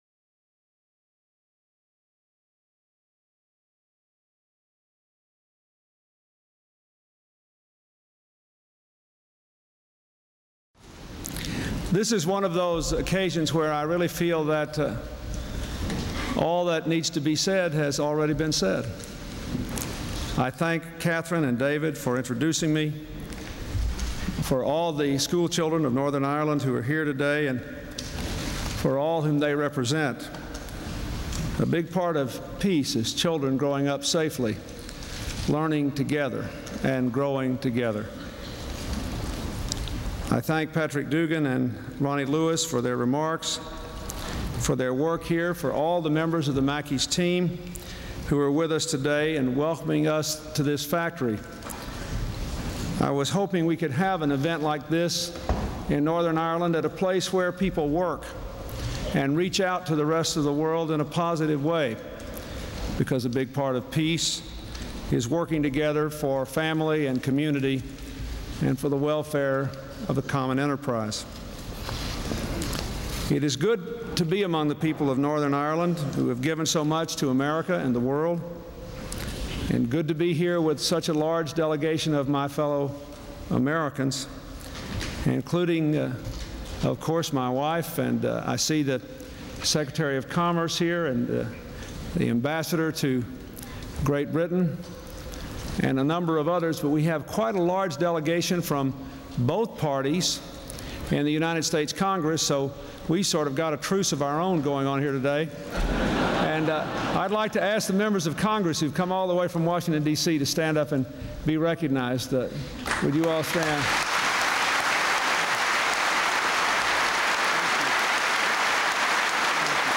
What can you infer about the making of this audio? November 30, 1995: Address to the Employees of the Mackie Metal Plant